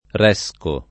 [ r $S ko ]